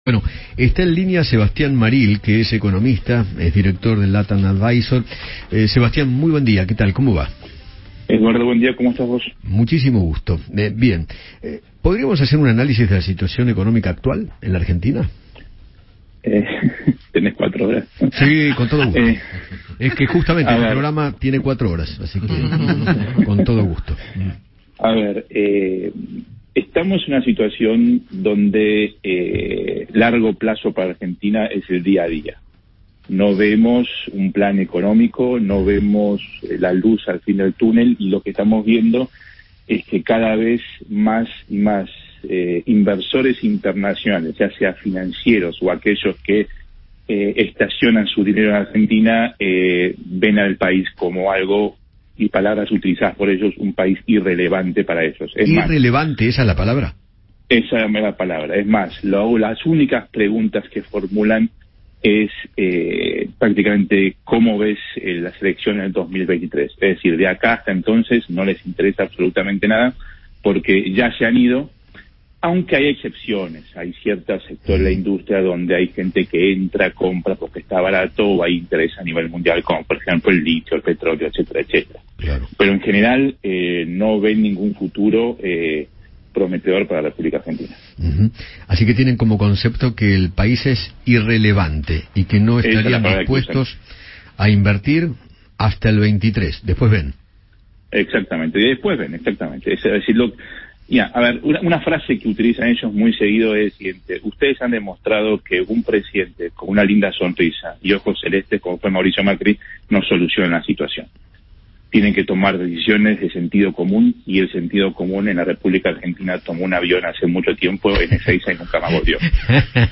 economista y analista internacional, conversó con Eduardo Feinmann sobre la situación económica de la Argentina y se refirió a la falta de credibilidad que tiene el país para los inversores.